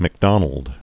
(mĭk-dŏnəld), George 1824-1905.